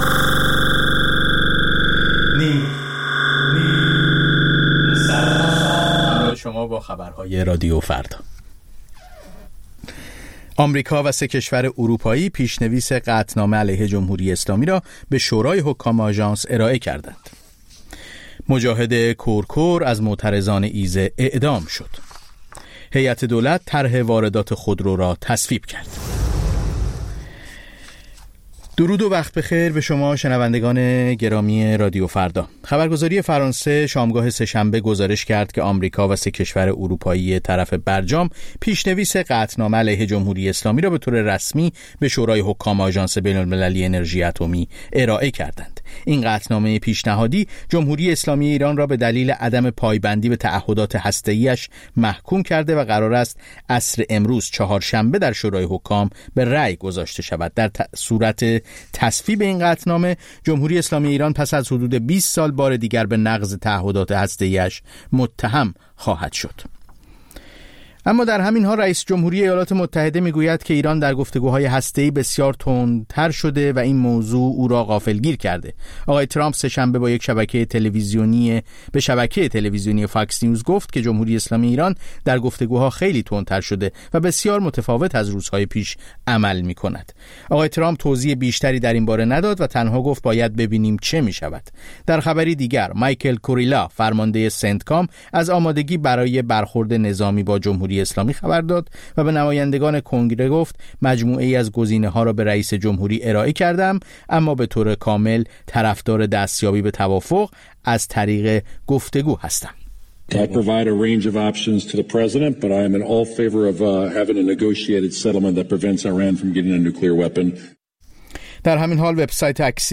سرخط خبرها ۱۲:۰۰